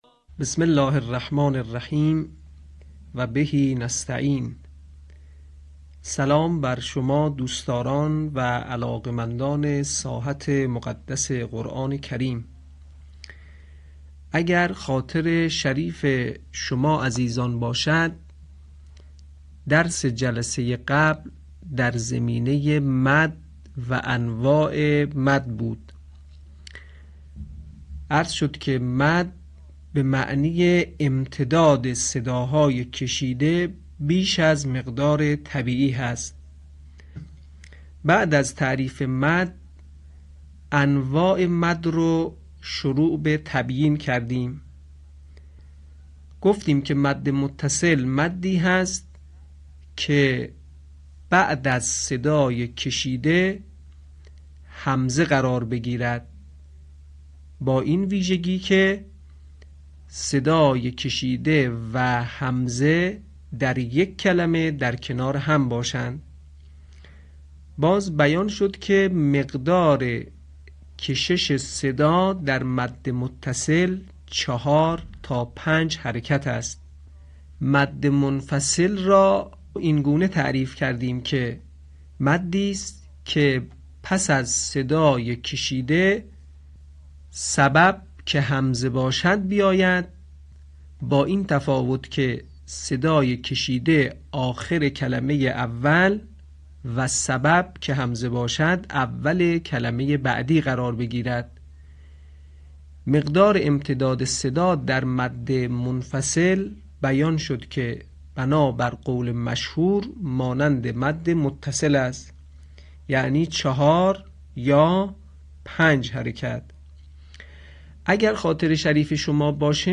صوت | آموزش انواع مد(۲)
به همین منظور مجموعه آموزشی شنیداری (صوتی) قرآنی را گردآوری و برای علاقه‌مندان بازنشر می‌کند.